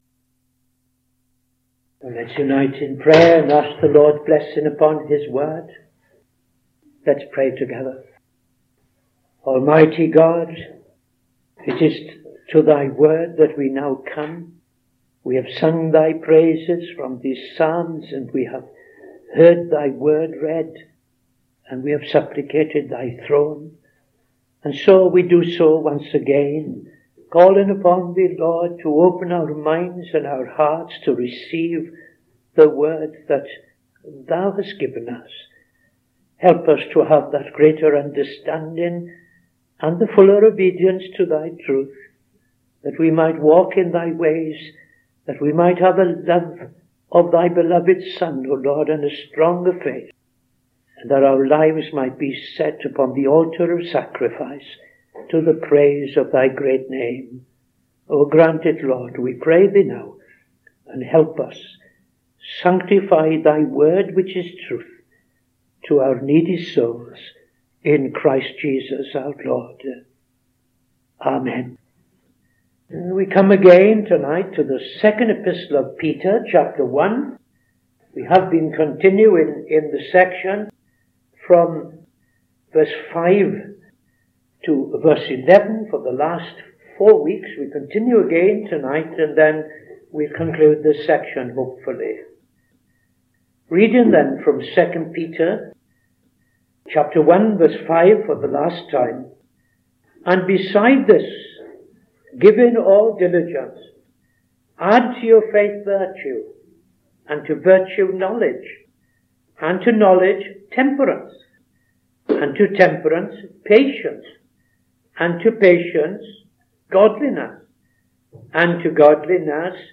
Opening Prayer and Reading II Peter 1:5-13